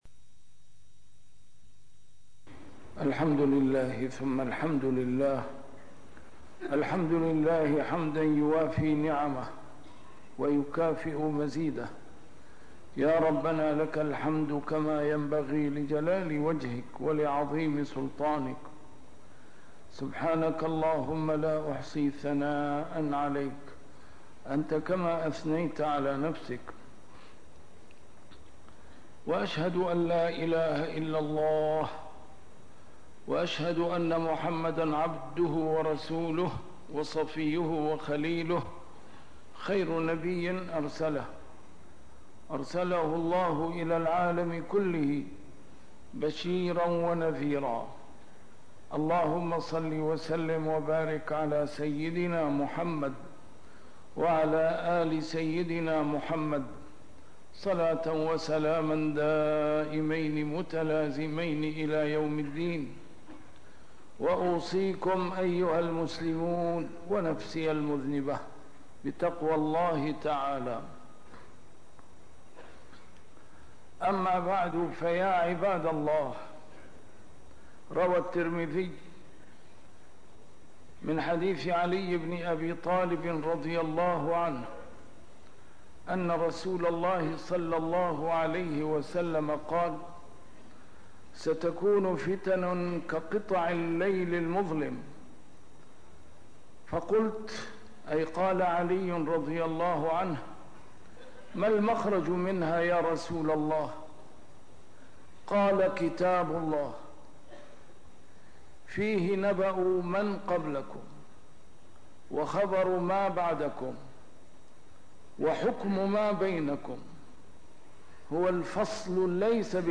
A MARTYR SCHOLAR: IMAM MUHAMMAD SAEED RAMADAN AL-BOUTI - الخطب - السبيل إلى التحرر من الضغوطات الخارجية